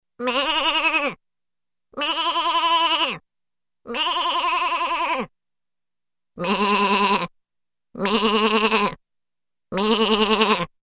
Звуки овечки
Блеяние овцы (подражание)